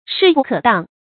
势不可当 shì bù kě dāng 成语解释 当：抵挡。
成语繁体 勢不可當 成语简拼 sbkd 成语注音 ㄕㄧˋ ㄅㄨˋ ㄎㄜˇ ㄉㄤ 常用程度 常用成语 感情色彩 中性成语 成语用法 主谓式；作谓语、定语；含褒义 成语结构 主谓式成语 产生年代 古代成语 成语正音 当，不能读作“dǎnɡ”或“dànɡ”。